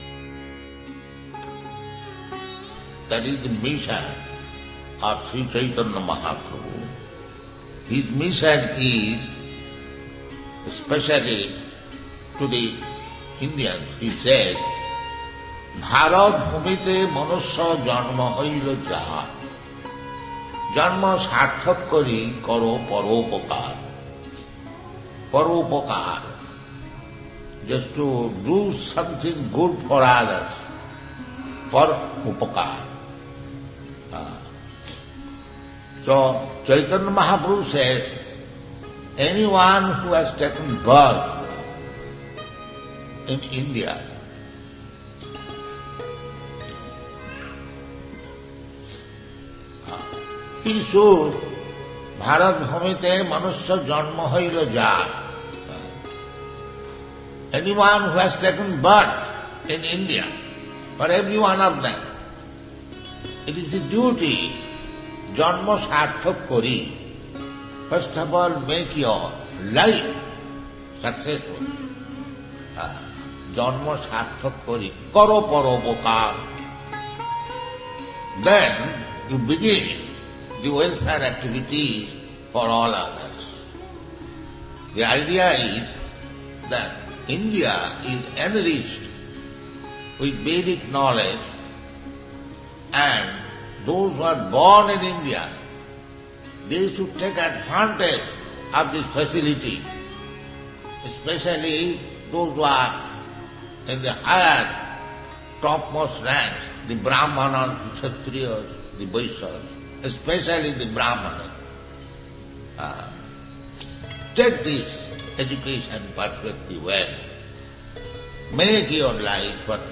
(760620 - Lecture BG 09.03 - Toronto)